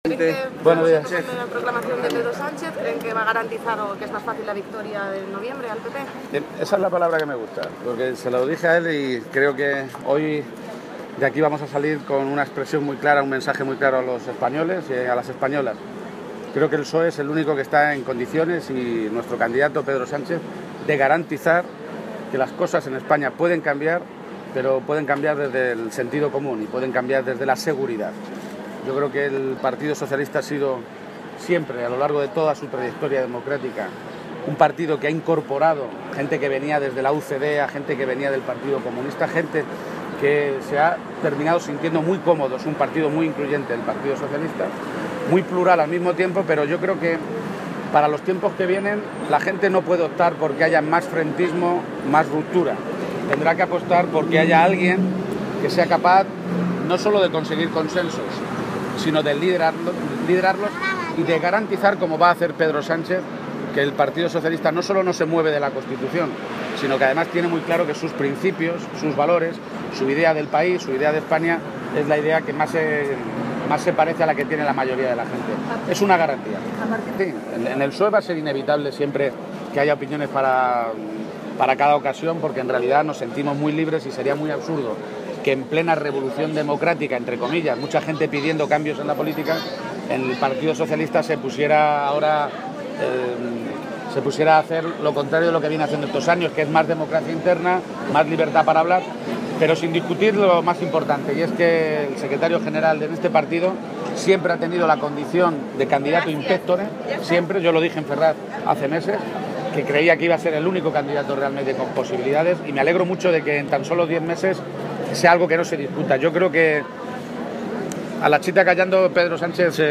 García-Page se pronunciaba de esta manera esta mañana, en Madrid, a preguntas de los medios de comunicación minutos antes de que comenzara la reunión del Comité Federal del PSOE que va a ratificar la candidatura de Sánchez al haber sido el único que ha recogido los avales para ser proclamado aspirante a La Moncloa.